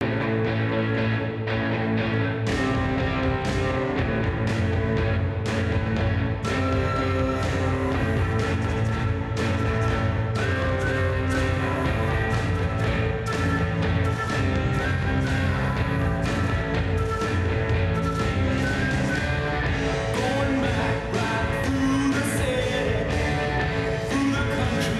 Impulse Reverb
3 short 24 bit stereo example wav files